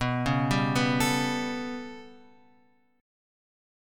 B 7th Suspended 2nd Sharp 5th